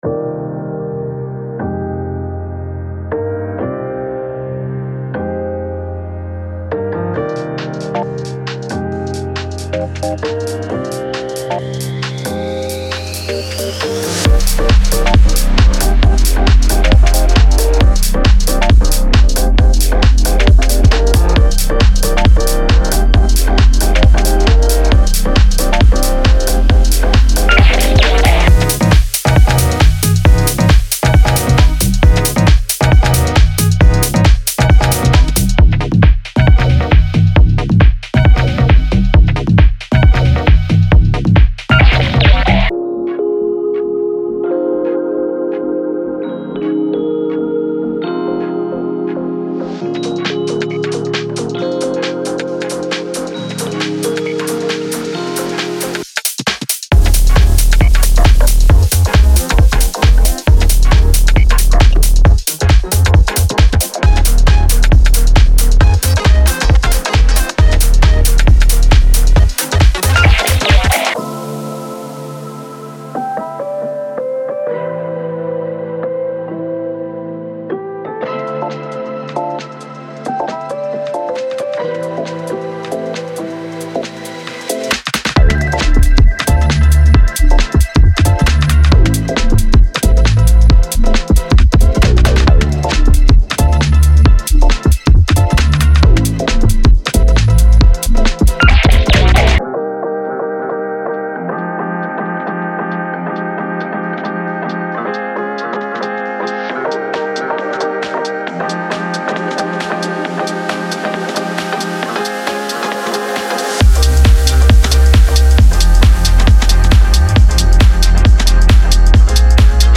ユニークなUK GarageとHouseのクロスオーバーサウンドを求めるプロデューサーにとって最適なパックです。
デモサウンドはコチラ↓
Genre:Garage